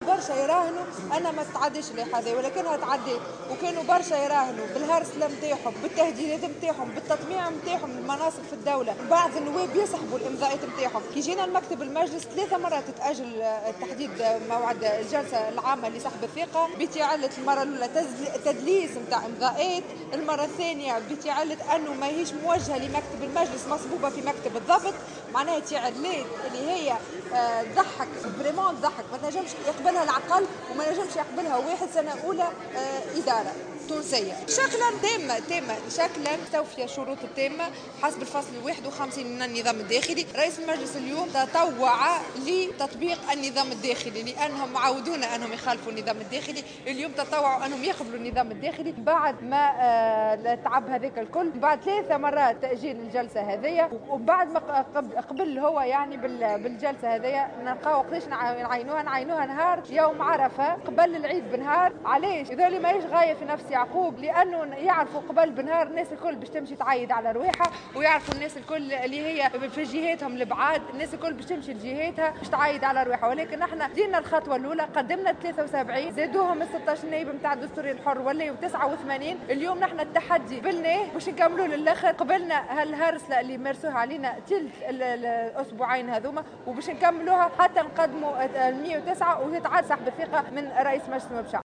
وأكدت العماري في تصريح للجوهرة أف أم، إن تعيين موعد الجلسة جاء بعد "هرسلة وتهديدات" واستماتة في البحث عن ذرائع لإسقاط اللائحة، بتعلة تزوير إمضاءات في مناسبة اولى، وبحجة إيداع اللائحة في مكتب الضبط وليس في مكتب المجلس في مناسبة ثانية، واصفة هذه التعلات بـ"المضحكة والتي لا يقبلها العقل".